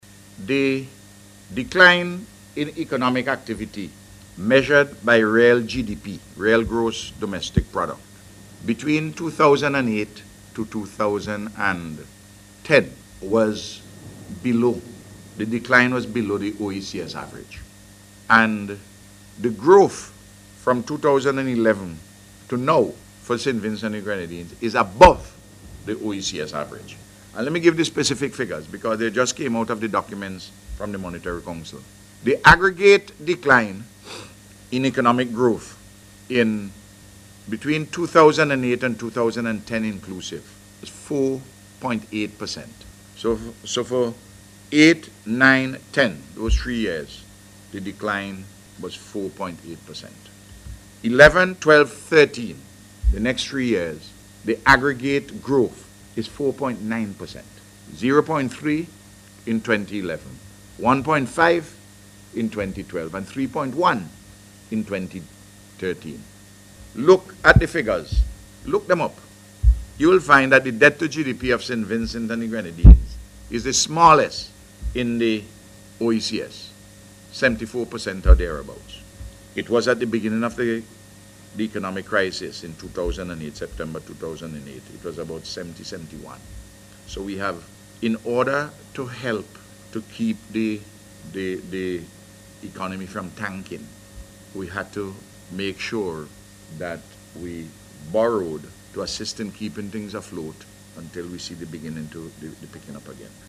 The Prime Minister made the point, as he responded to a question from the media at a News Conference this week.